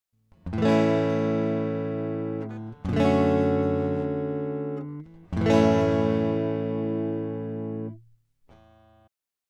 ギター/ライン録り
minifuse1_Gt-Rec-1.wav